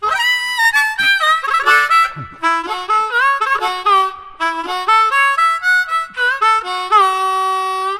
描述：录音室里的口琴，来自德克萨斯州的奥斯汀。
所有的文件都是用的"C"调，速度为150bpm。 这些样本是未经处理的，没有EQ和FX，用大振膜电容麦克风录制。
标签： 环境 - 声音的研究 蓝调竖琴 口琴 未处理
声道立体声